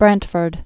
(brăntfərd)